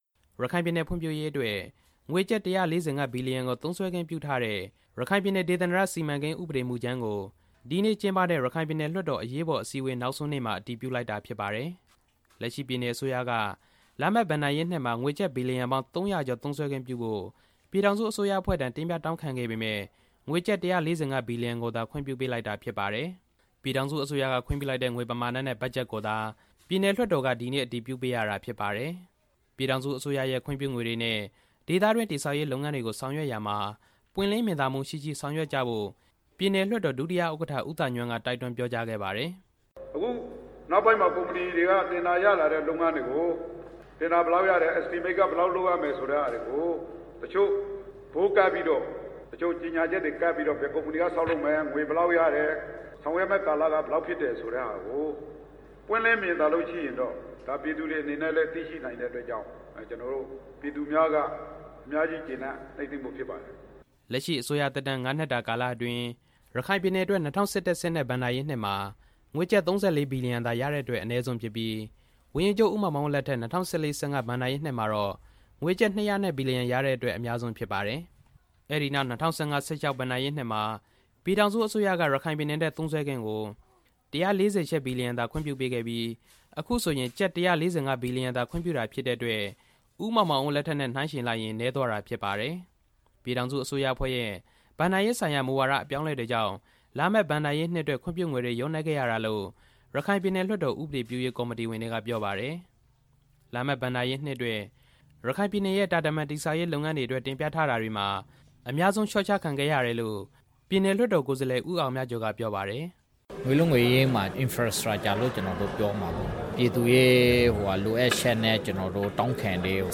ရခိုင်ပြည်နယ်အတွက် ငွေကျပ် ဘီလီယံပေါင်း ၃၀၀ ကျော် သုံးစွဲခွင့်ပြုဖို့ တင်ပြခဲ့ပေမယ့် ပြည်ထောင်စုအစိုးရ က ငွေကျပ် ၁၄၅ ဘီလီယံသာ ခွင့်ပြုပေးလိုက်တာဖြစ်တယ်လို့ ပြည်နယ်လွှတ်တော်ကိုယ်စားလှယ် ဦးအောင်ဝင်းက ပြောပါတယ်။